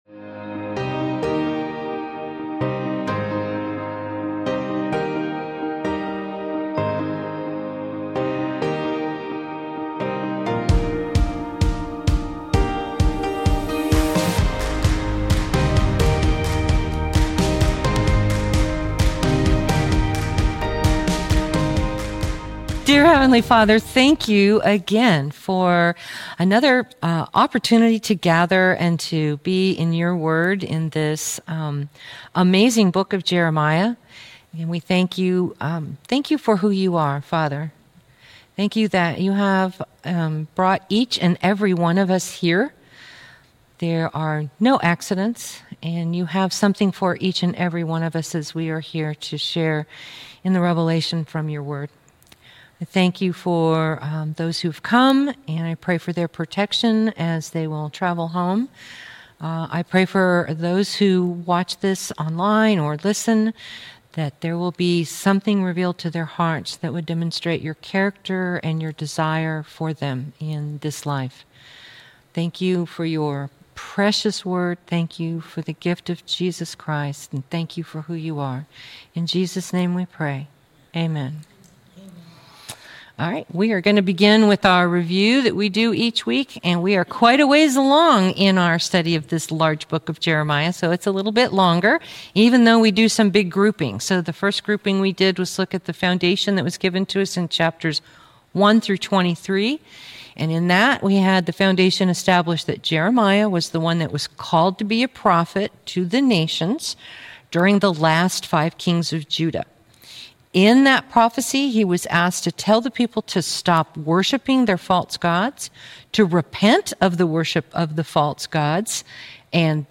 Jeremiah - Lesson 39-40 | Verse By Verse Ministry International